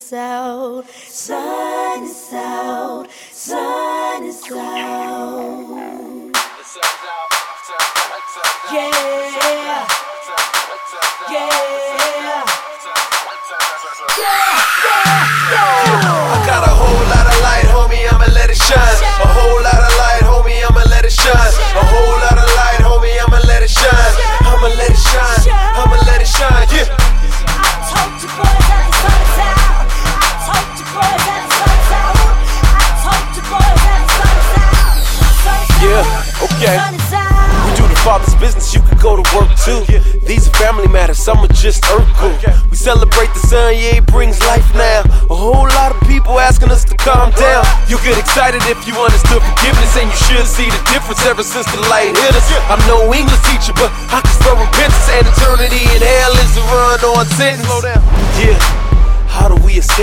Rock-, Pop-, Motown-, Soul- und Black Gospel-Einflüsse
• Sachgebiet: Pop